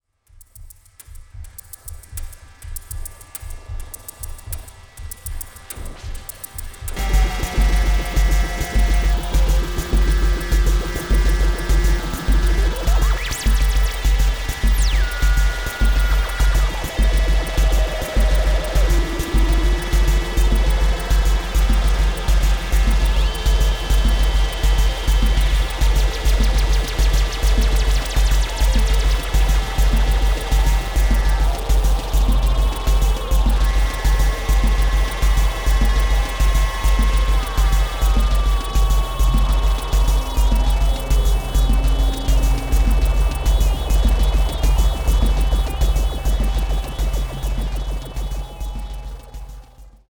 Unmastered version: